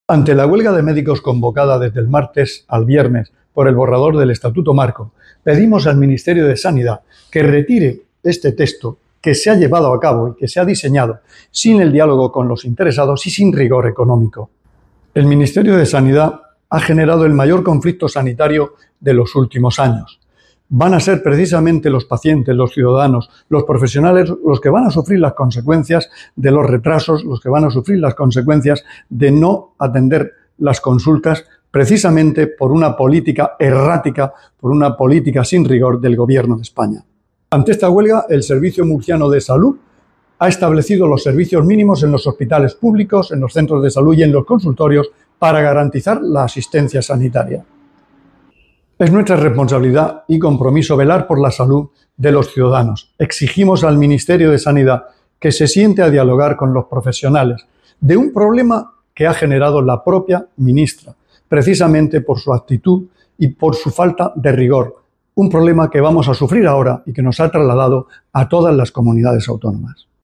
Sonido/ Declaraciones del consejero de Salud, Juan José Pedreño, sobre la huelga convocada en contra del borrador del Estatuto Marco del Ministerio de Sanidad.